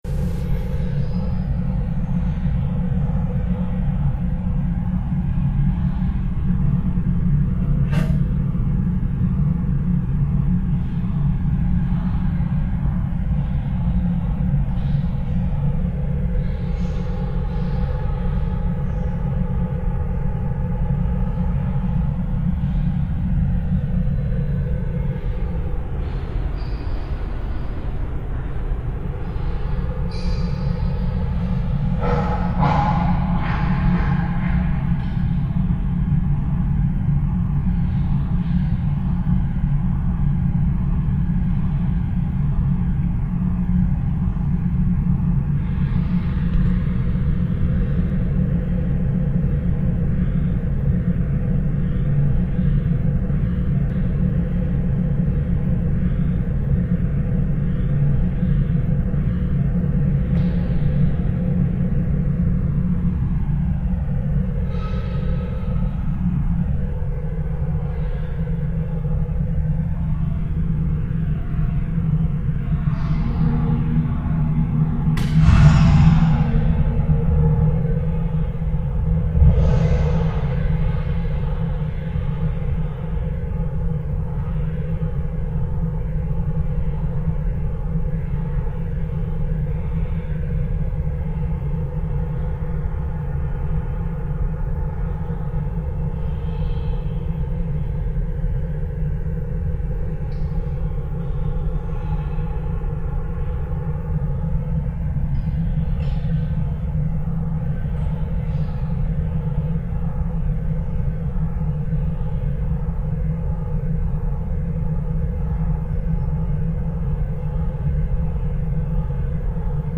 acoustic views of the interior of works of Art
A minimum of sound processing is used on these recordings